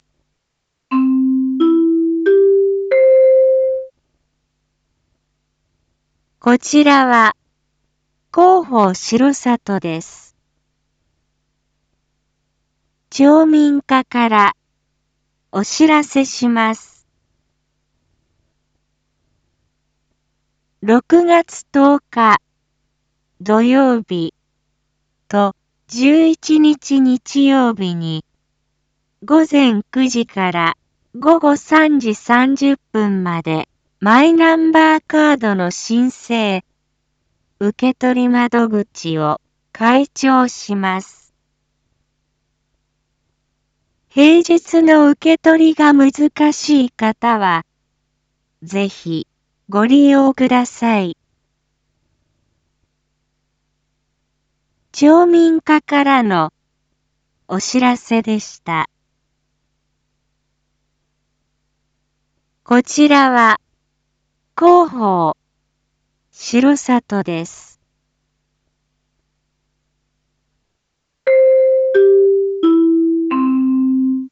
一般放送情報
Back Home 一般放送情報 音声放送 再生 一般放送情報 登録日時：2023-06-09 19:01:15 タイトル：Ｒ5.6.9 19時放送分 インフォメーション：こちらは、広報しろさとです。